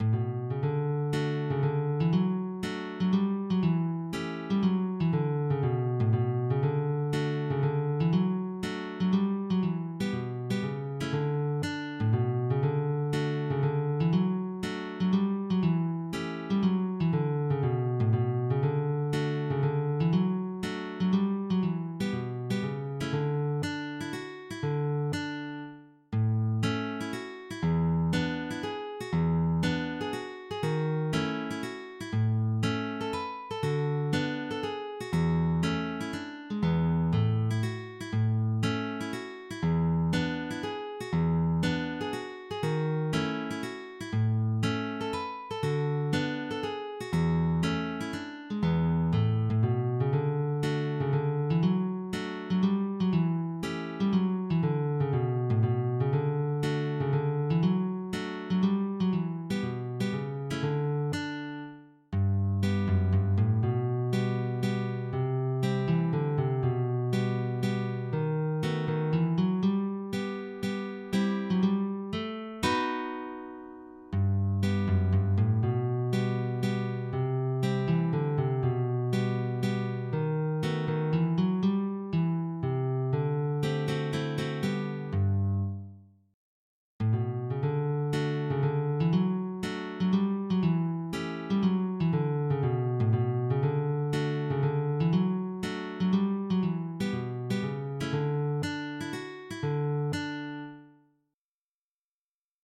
alba_op14-fannycilla_mazurka.mid.mp3